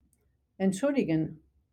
to excuse entschuldigen (ent-SCHUL-di-gen)